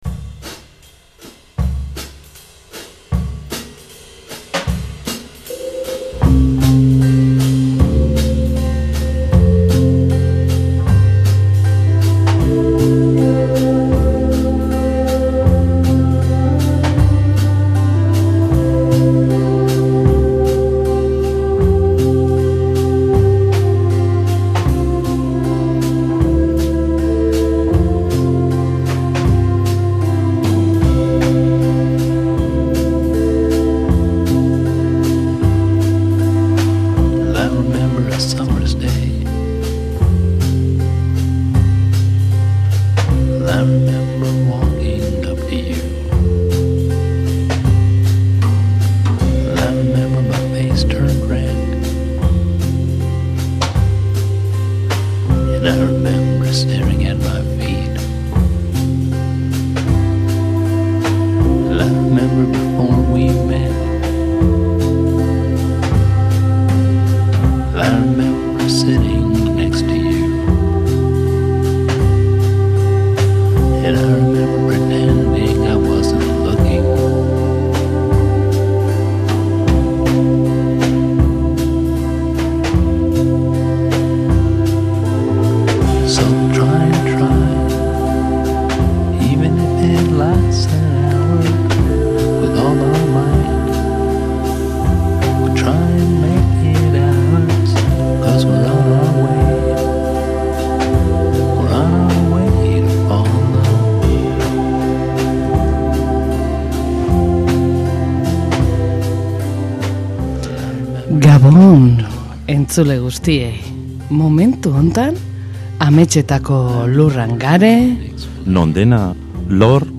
Denboraldi berriaren estreinaldi honetan hirukotea izango gara; partaide berri baten ahotsa entzungo duzue.
Gaurko gaia: Uda. Hori bai, guzti-guztia musikarekin lagunduta, betiko moduan.